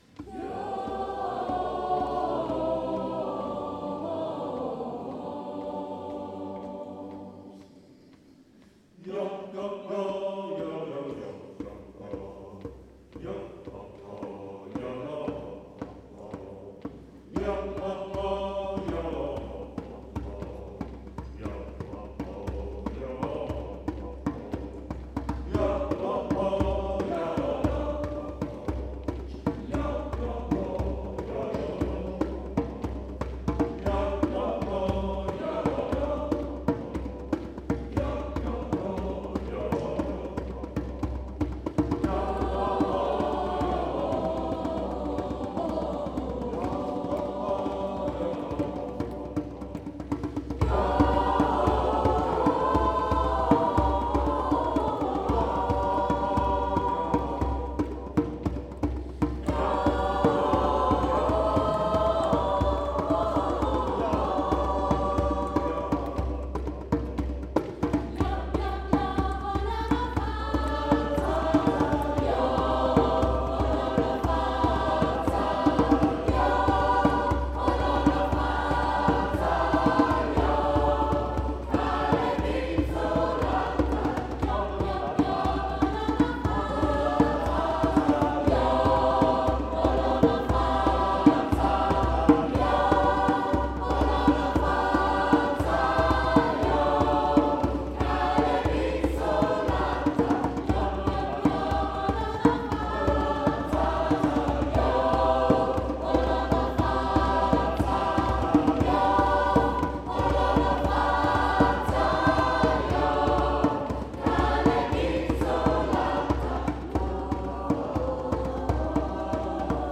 Mit rhythmischen Bewegungen zu afrikanischen Liedern
Die afrikanischen Lieder aus dem Gottesdienst